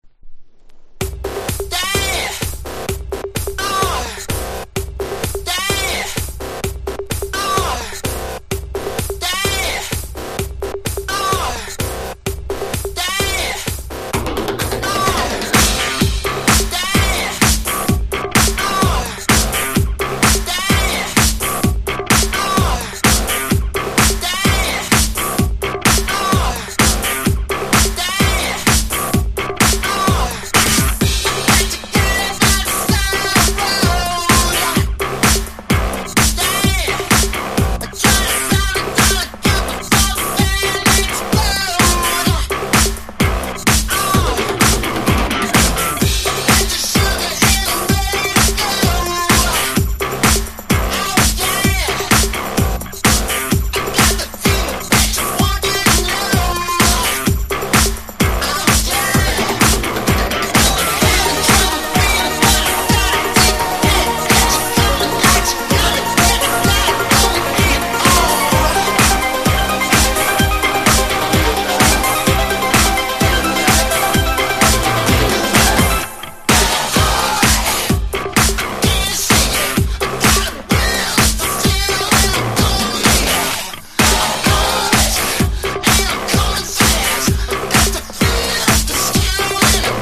前作からうって変ってアタックの強いエレクトロ色濃い作品！！